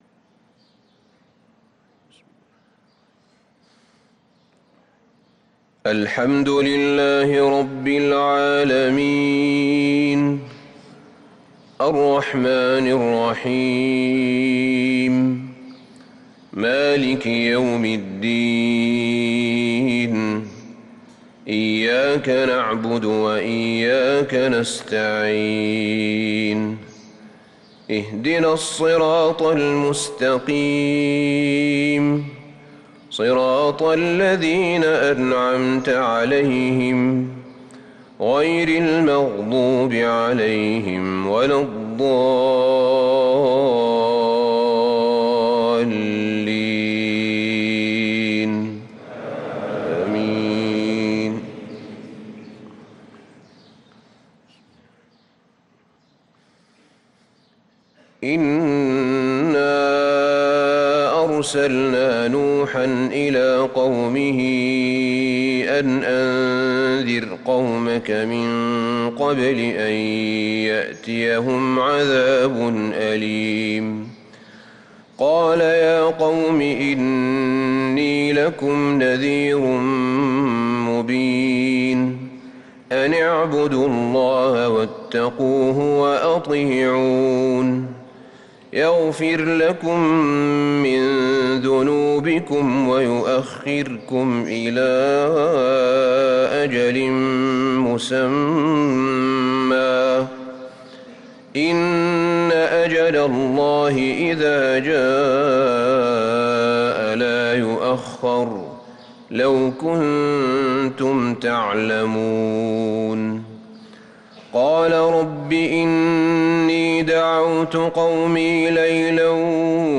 صلاة الفجر للقارئ أحمد بن طالب حميد 27 رجب 1444 هـ
تِلَاوَات الْحَرَمَيْن .